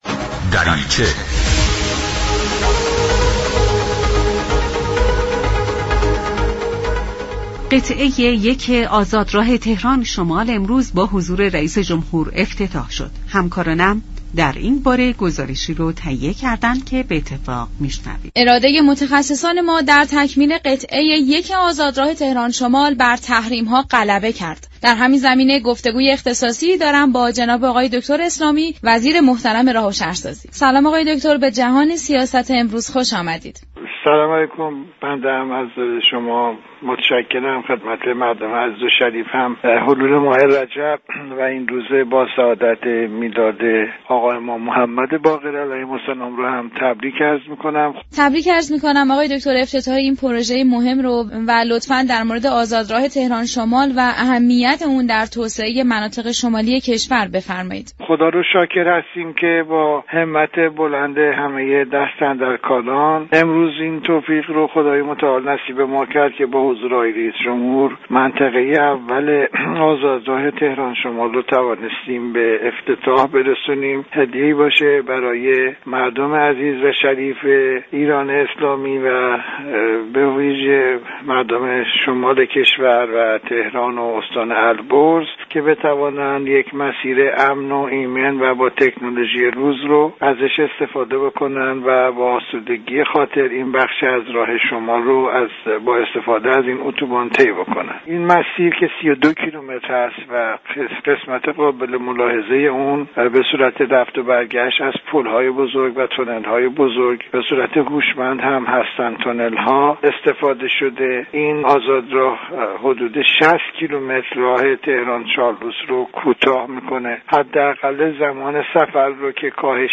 به گزارش شبكه رادیویی ایران، دكتر «محمد اسلامی» وزیر راه و شهرسازی در برنامه «جهان سیاست» درباره افتتاح قطعه 1 آزادراه تهران شمال گفت: افتتاح آزادراه تهران شمال كه با حضور حسن روحانی رییس جمهور افتتاح شد، هدیه ای از سوی دولت به مردم بوده است.